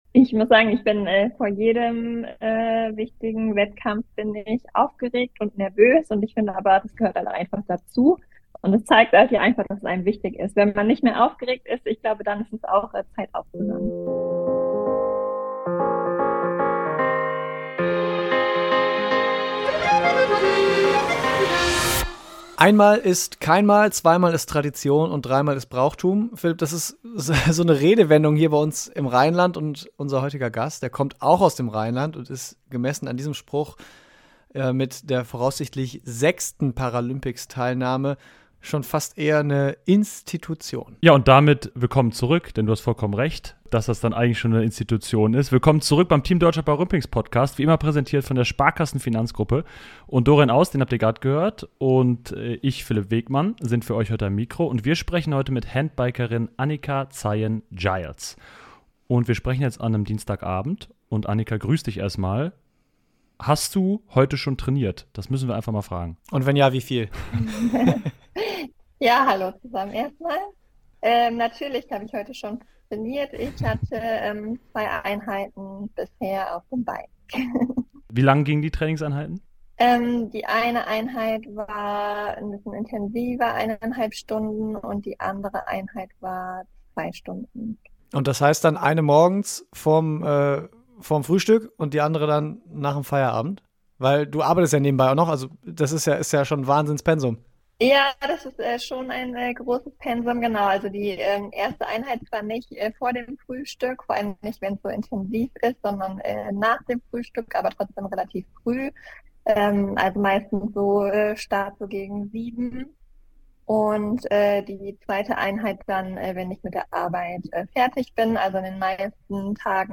Im Team Deutschland Paralympics Podcast erzählt sie, warum sie keine Basketball-Karriere in den USA angestrebt hat, warum 2023 für sie wie eine Achterbahnfahrt war und warum Radsport auch ein Teamsport ist. Aufgrund von technischen Problemen ist der Ton bei Annika in der ersten Viertelstunde leider nicht in gewohnter Qualität.